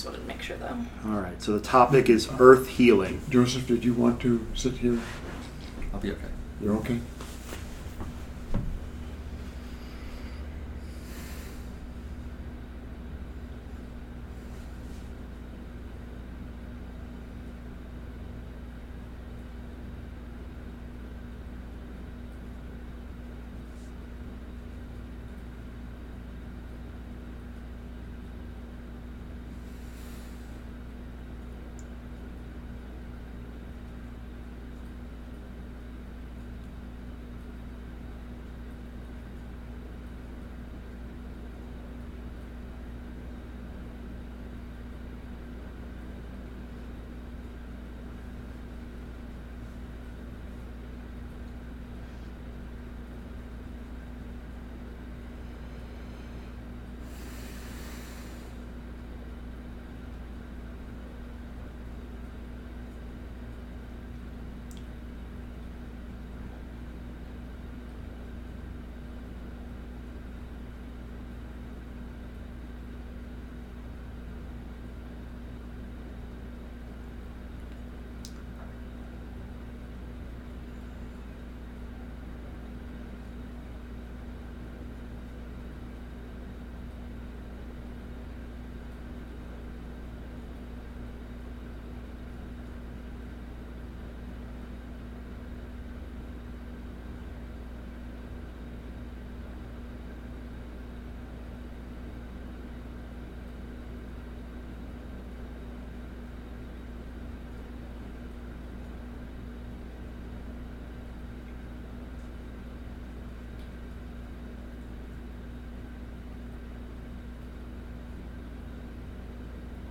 In this session from the Other Selves Working Group’s first channeling intensive, those of Laitos describe Earth’s transition from third density to fourth density as a birthing process experiencing difficulty and requiring aid from her inhabitants. The seeker is capable of providing love, comfort, and assistance by maintaining a welcoming, nurturing, and peaceful attitude in the face of what may appear to be catastrophe.